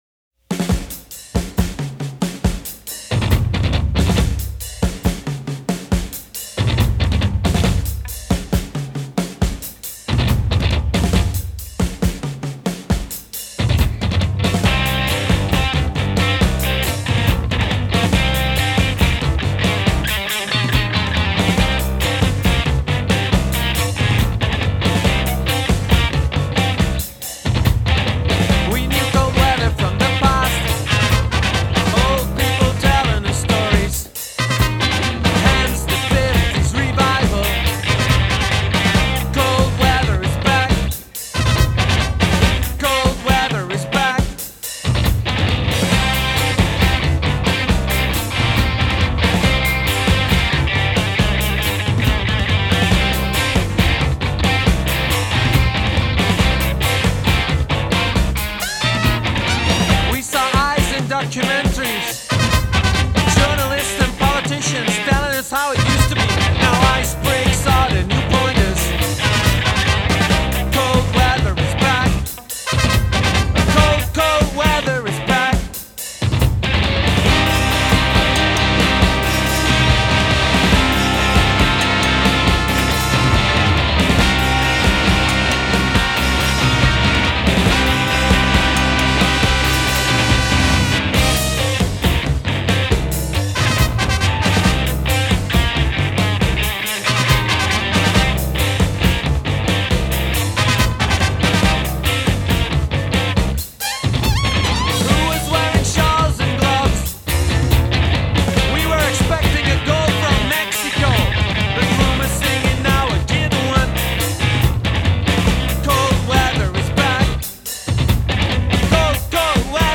recorded in Chicago